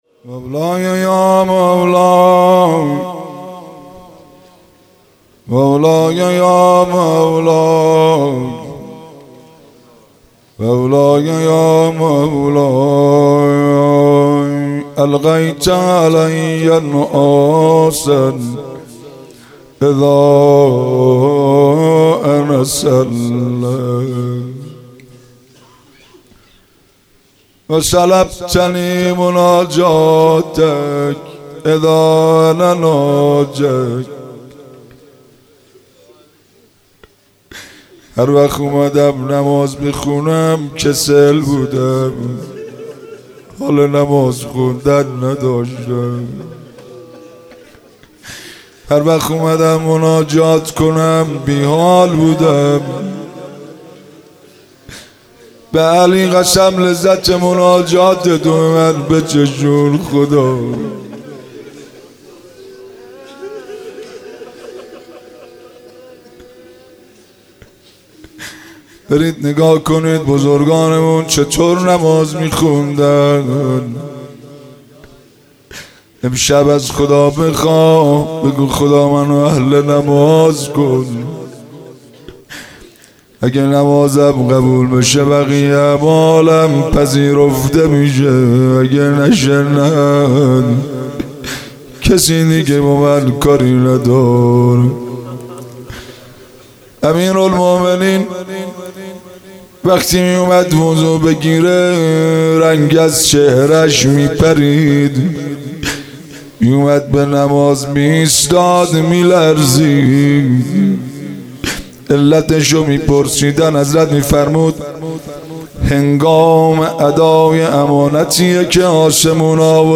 مناسبت : شب نوزدهم رمضان - شب قدر اول
قالب : مناجات